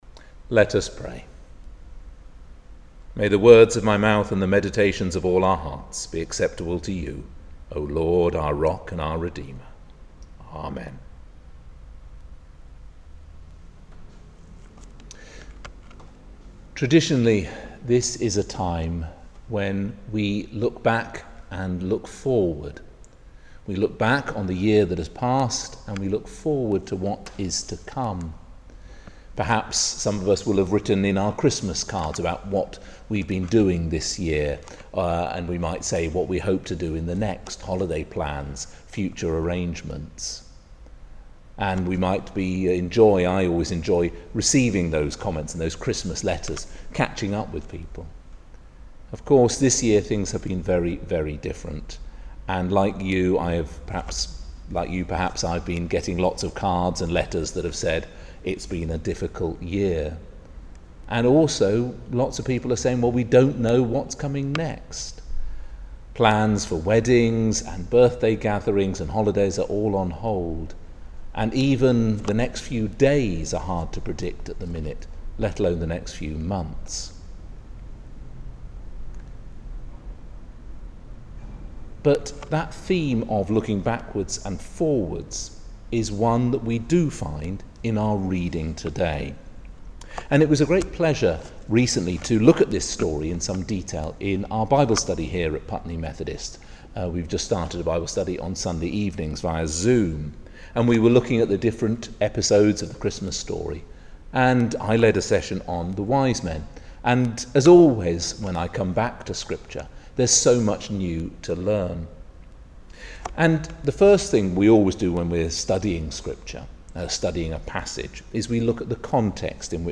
latestsermon-3.mp3